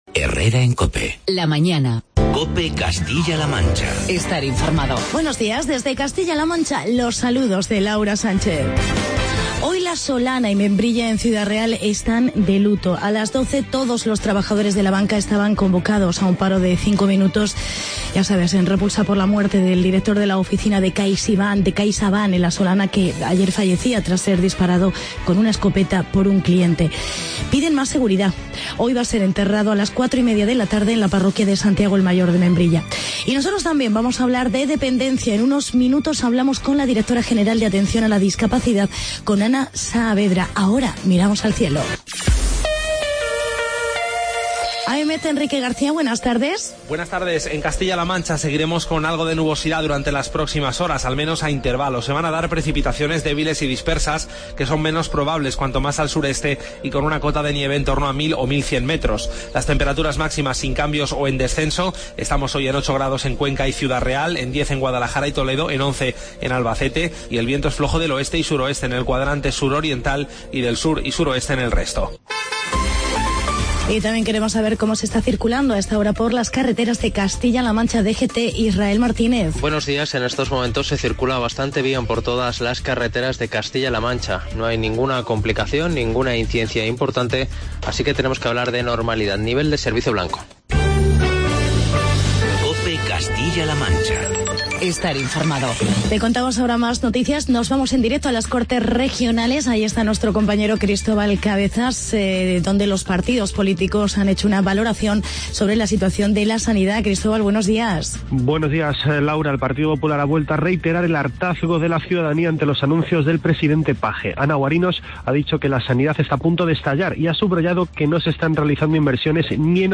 Actualidad y entrevista con Ana Saavedra, Directora General de Atención a la Dependencia.